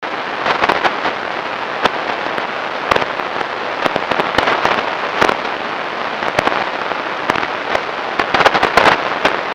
Вкратце, есть подозрения на внеземное происхождение сигналов похожих на так называемые сферики (импульсы от грозовых разрядов) 9 секундный аудиофайл
По мне, так ливень с градом по крыше.
Unidentified_atmospherics.mp3